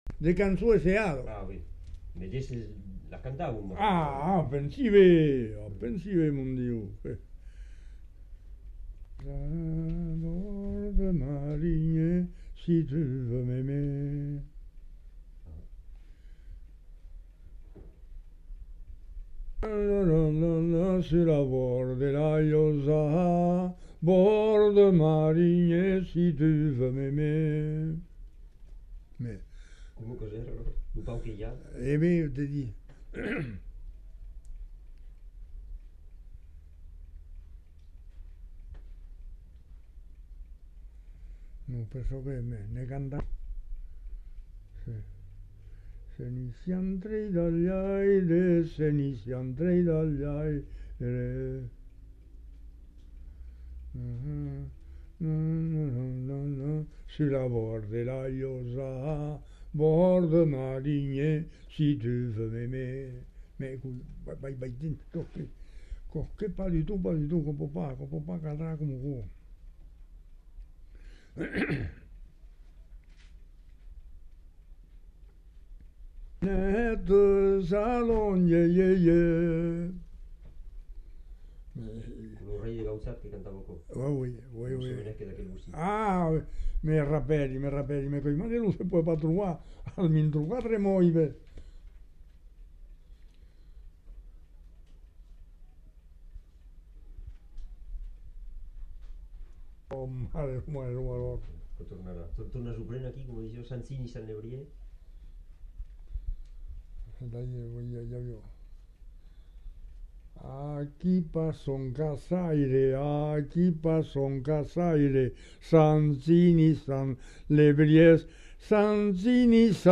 Aire culturelle : Haut-Agenais
Genre : chant
Effectif : 1
Type de voix : voix d'homme
Production du son : chanté
Notes consultables : Incipit coupé à l'enregistrement.